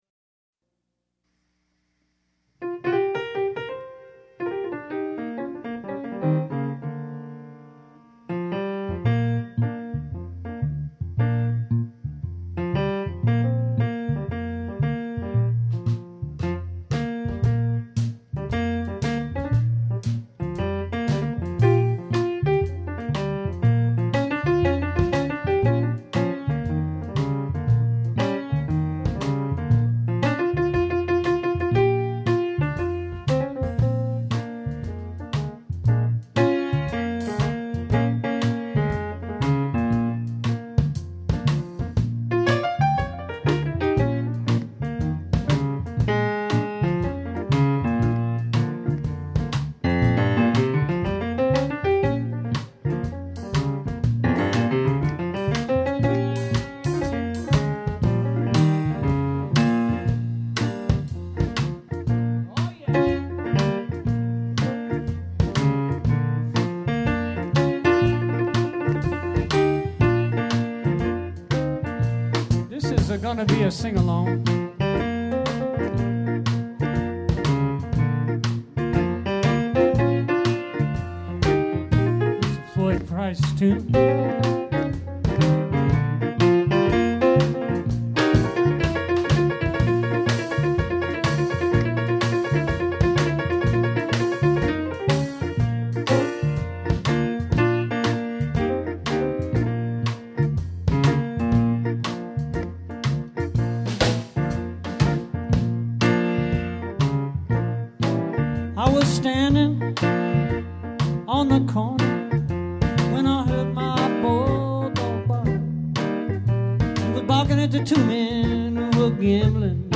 from our session at The Musicians’ Union Hall: Stagger Lee – The San Francisco Medicine Ball Band